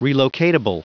Prononciation du mot relocatable en anglais (fichier audio)
Prononciation du mot : relocatable